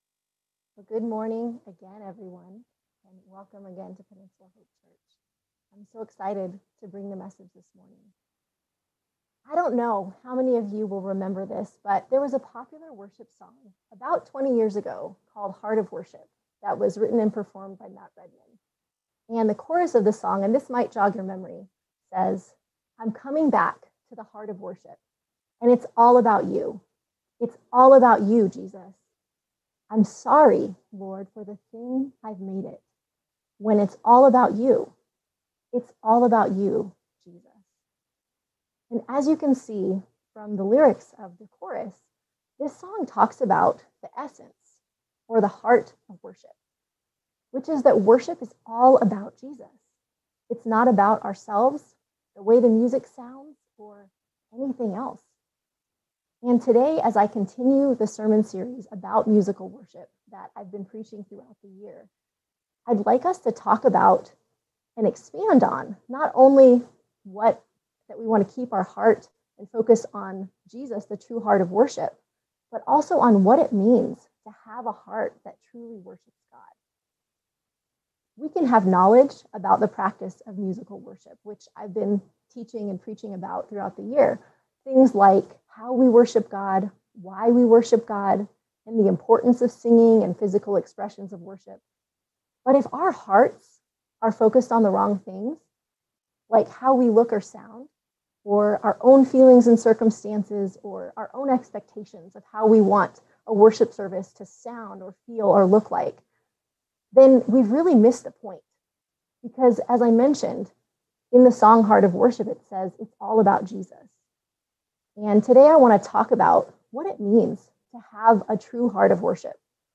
August 1, 2021 Sunday Worship Service
august-1-2021-sunday-worship-service.mp3